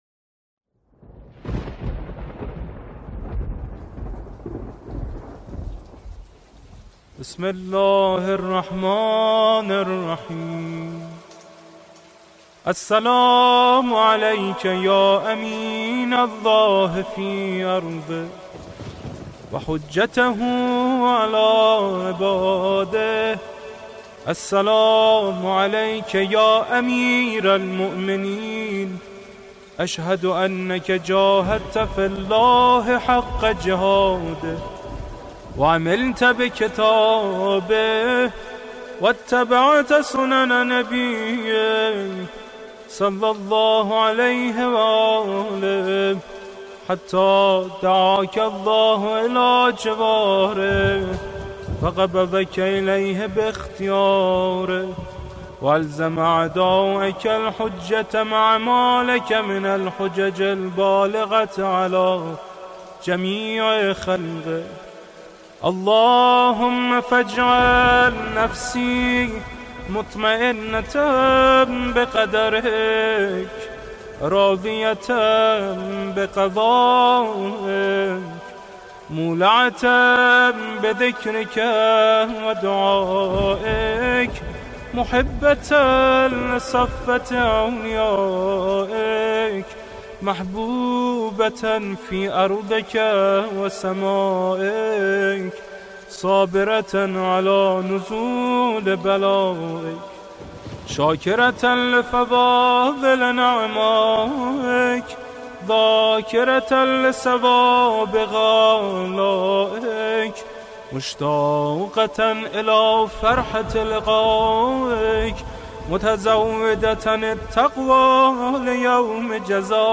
الرادود علي فاني